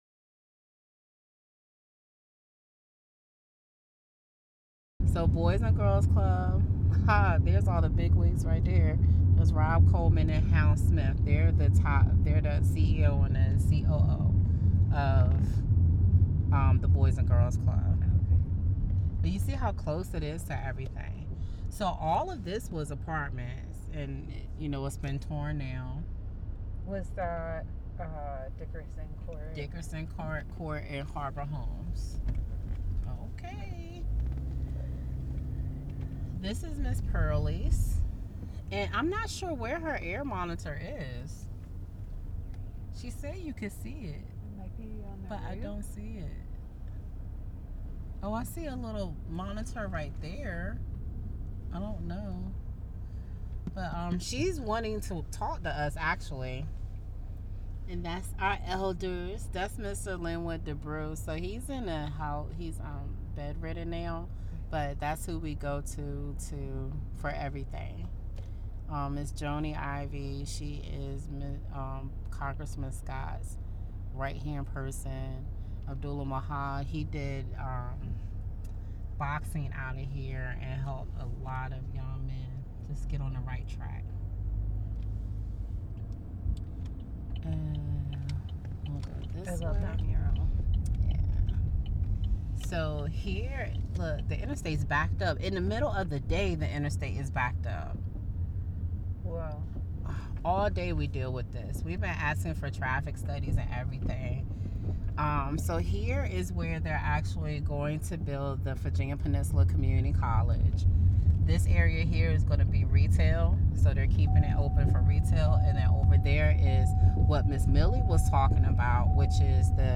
The trip starts at the East End Boys and Girls Club, then crosses under I-664 to loop around part of Dominion Terminal.
Interview.